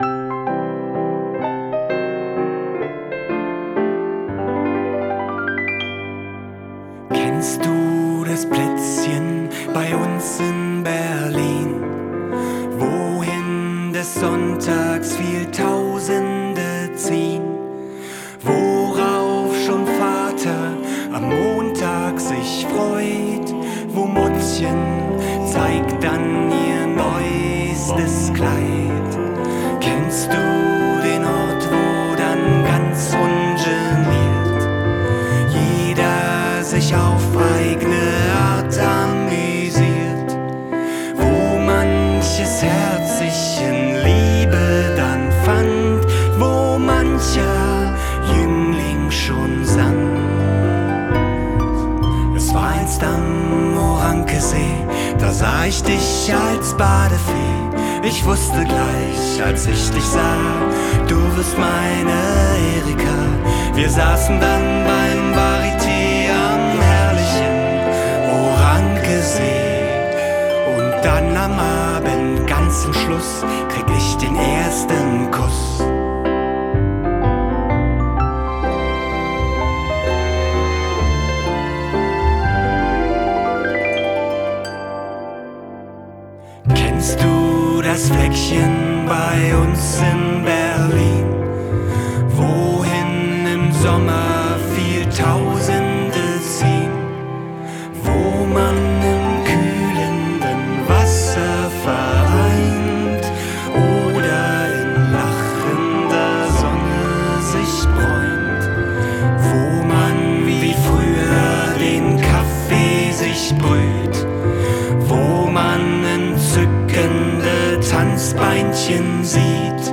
Orankewalzer mit Gesang als wav.
orankewalzer-mit-gesang.wav